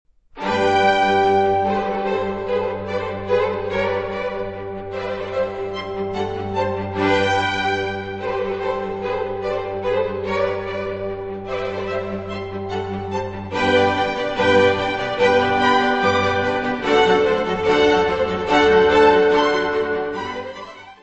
Music Category/Genre:  Classical Music
Allegro.